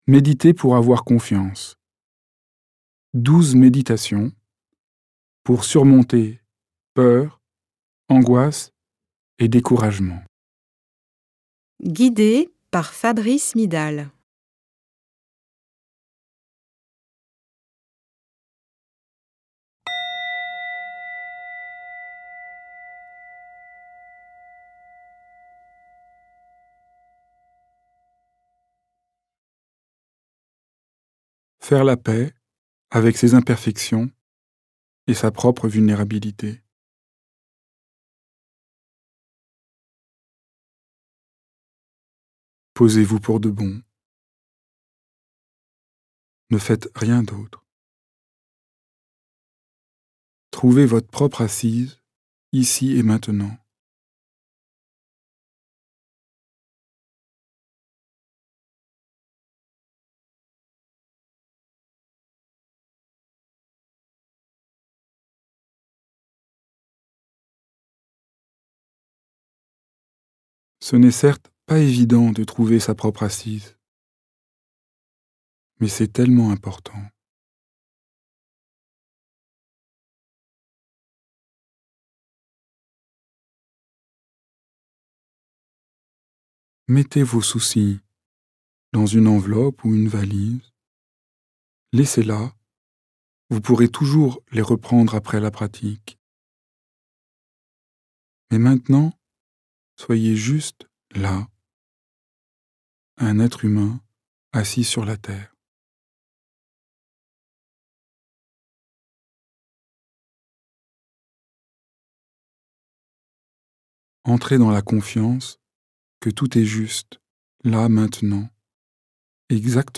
En commençant simplement par faire l’expérience de la confiance grâce à ces 12 méditations guidées inédites.